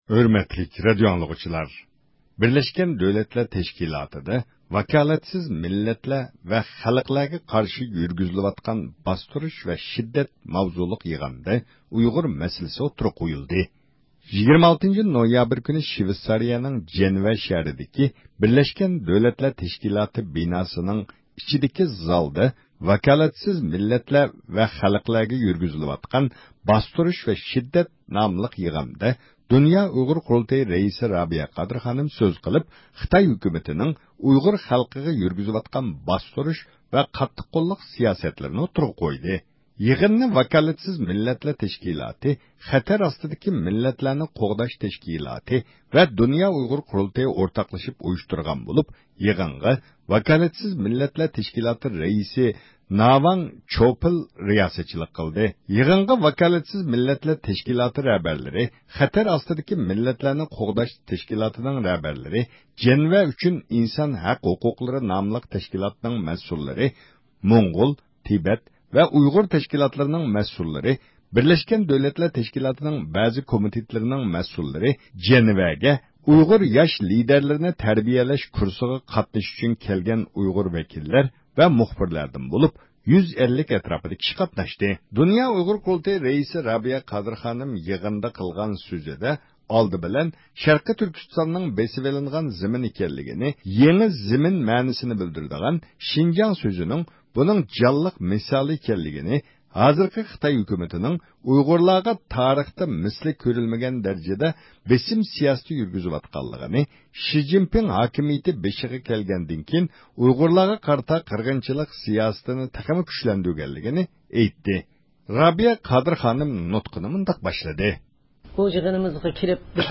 «ۋاكالەتسىز مىللەتلەر ۋە خەلقلەرگە قارشى يۈرگۈزۈلۈۋاتقان باستۇرۇش ۋە شىددەت» ناملىق يىغىندا د ئۇ ق رەئىسى رابىيە قادىر خانىم سۆز قىلدى. 2014-يىلى 26-نويابىر، جەنۋە.
26-نويابىر كۈنى شىۋېتسارىيەنىڭ جەنۋە شەھىرىدىكى ب د ت بىناسىنىڭ ئىچىدىكى زالدا ئېچىلغان «ۋاكالەتسىز مىللەتلەر ۋە خەلقلەرگە يۈرگۈزۈلۈۋاتقان باستۇرۇش ۋە شىددەت» ناملىق يىغىندا دۇنيا ئۇيغۇر قۇرۇلتىيى رەئىسى رابىيە قادىر خانىم سۆز قىلىپ، خىتاي ھۆكۈمىتىنىڭ ئۇيغۇر خەلقىگە يۈرگۈزۈۋاتقان باستۇرۇش ۋە قاتتىق قوللۇق سىياسەتلىرىنى ئوتتۇرىغا قويدى.